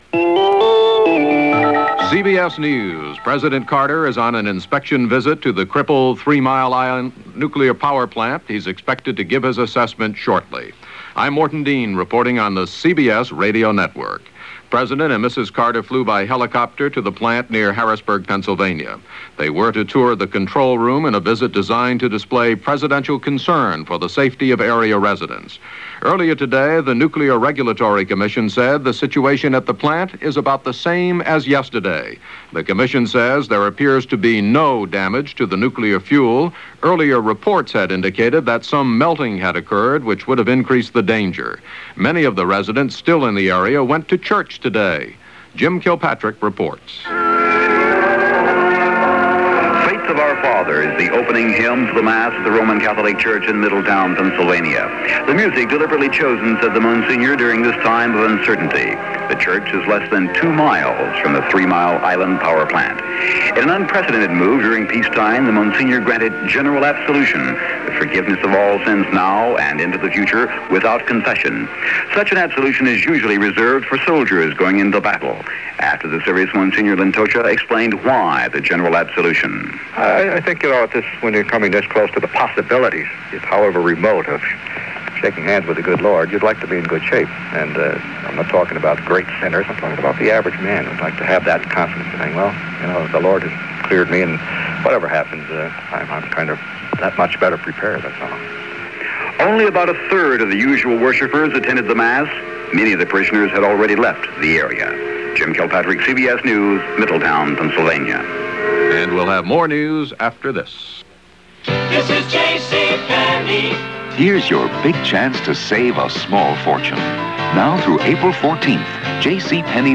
News – Bulletins – Carter Press Conference
He emerged later to give an impromptu Press Conference and tried his best to allay fears. not only to the people living within a five-mile radius of the damaged plant, but also to the American people, who were nervously eyeing similar Nuclear Power plants in their own cities and towns.